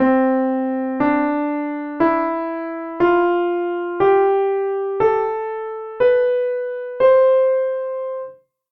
C-D-E-F-G-A-B-C
C Major Ascending:
C_Major_ascending.mp3